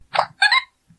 Diddy_Kong_Wiimote_Sound.ogg.mp3